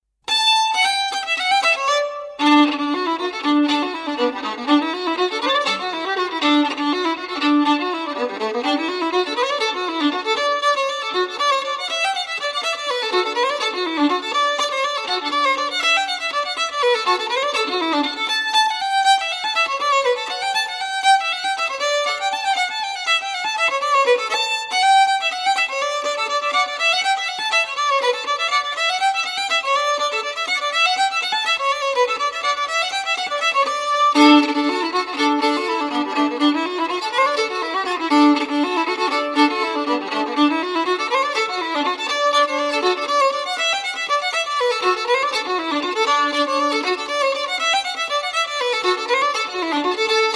violon
accordéon
flûtes, cornemuse, bodhran, cuillers
bouzouki
violoncelle
clavecin, piano
flûtes, guitare
Reel américain, 32 mes.